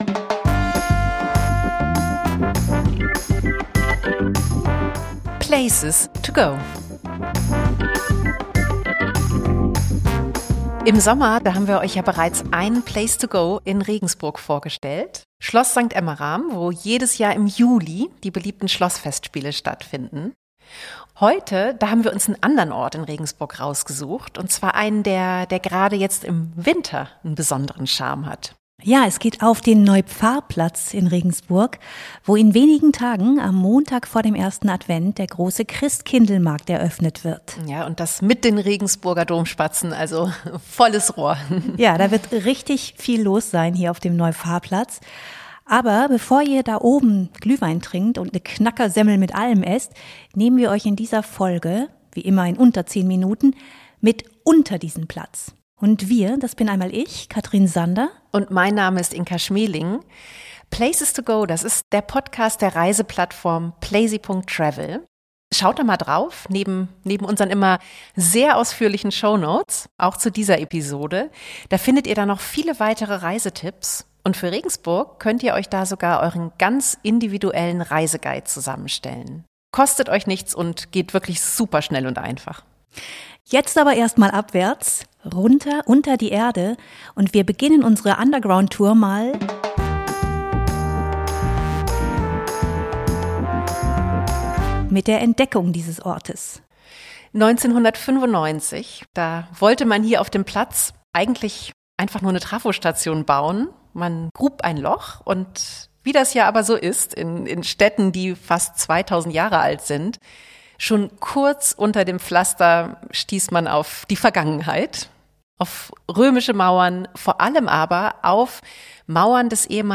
die beiden Hosts